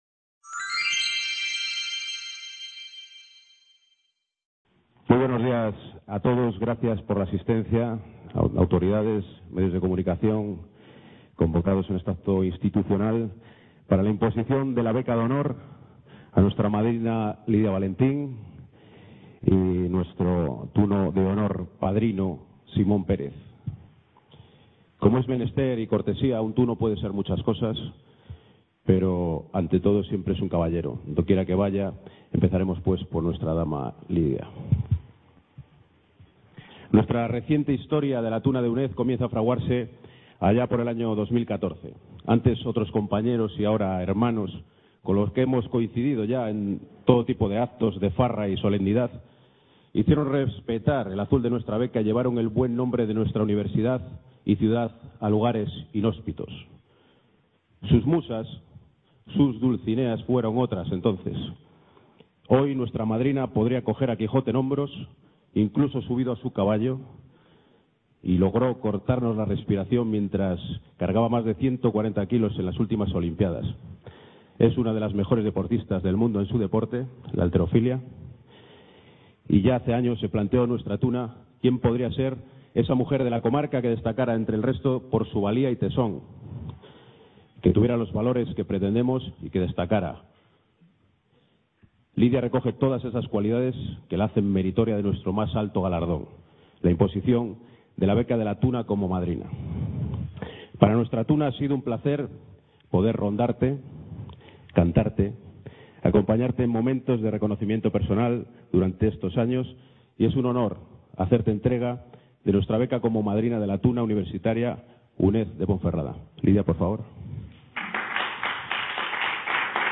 en la UNED de Ponferrada